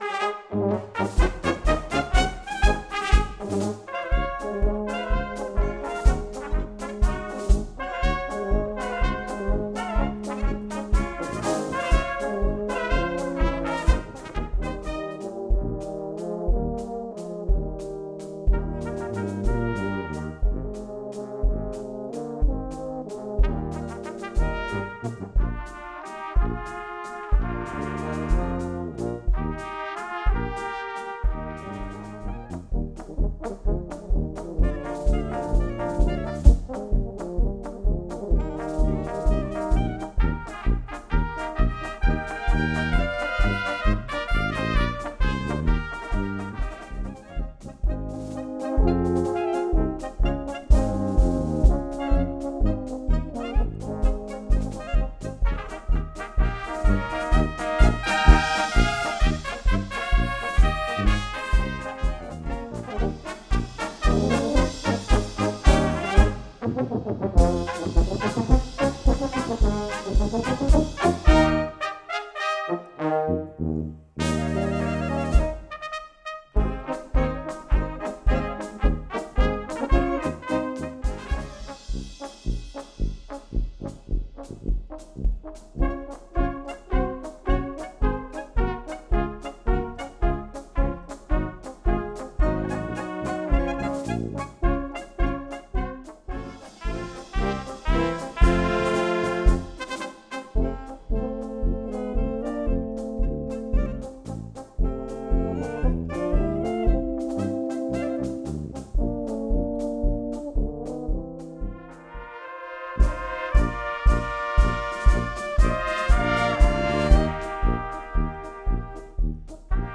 Böhmische Blasmusik made in Sachsen-Anhalt